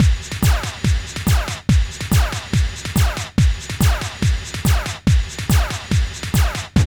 06.1 LOOP1.wav